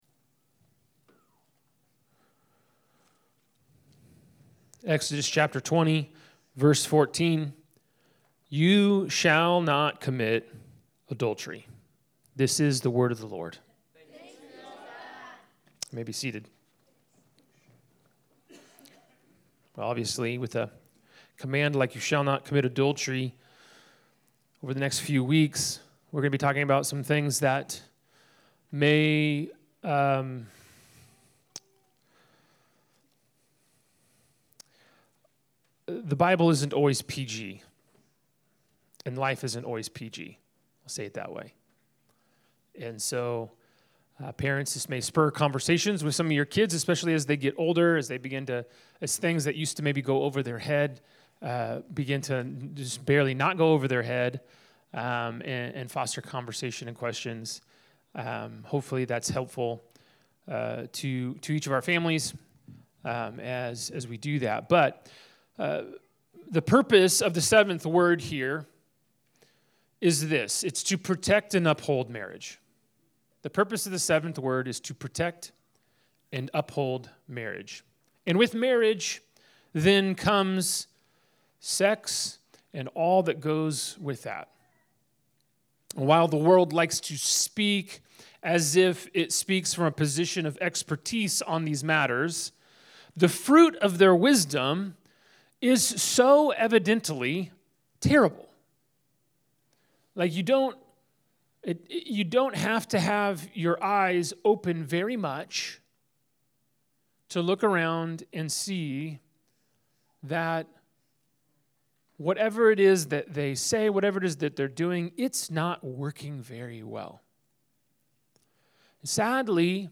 Proclaim Church Sermons Podcast - The Seventh Word... Pt. 1 | Free Listening on Podbean App